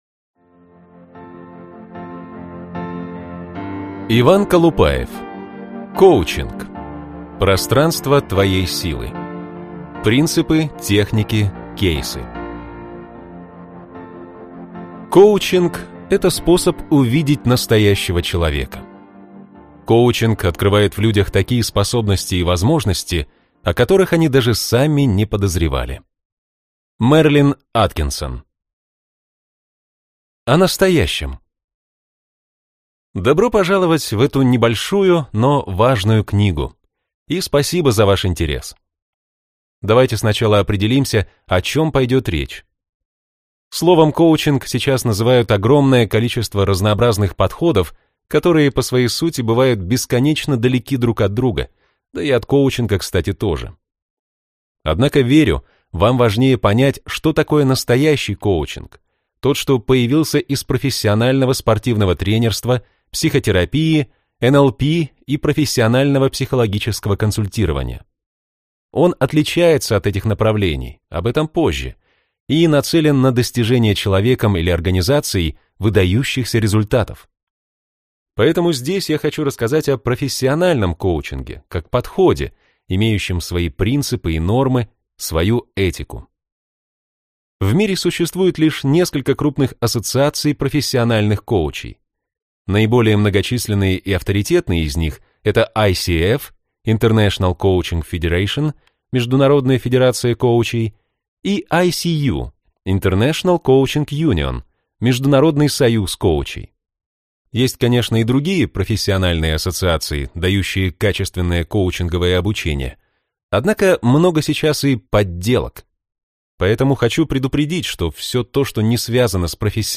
Аудиокнига Коучинг. Пространство твоей силы. Принципы, техники, кейсы | Библиотека аудиокниг